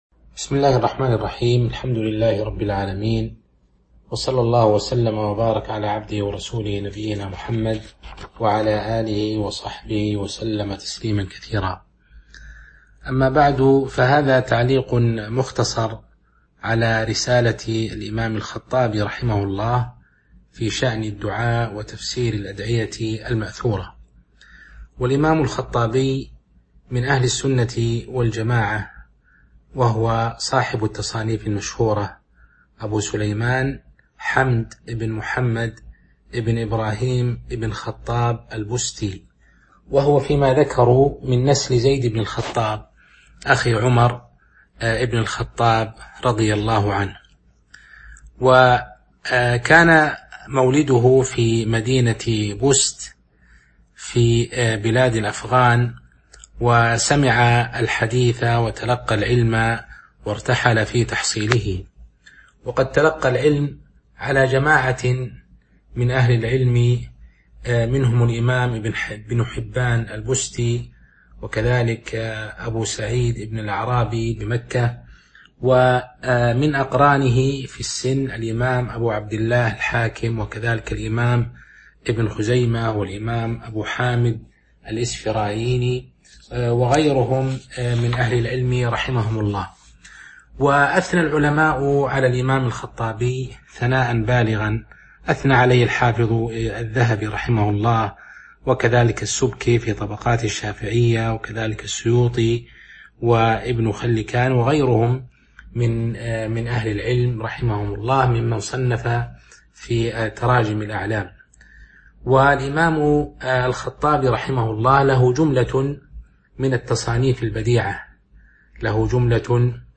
تاريخ النشر ٦ رمضان ١٤٤٢ هـ المكان: المسجد النبوي الشيخ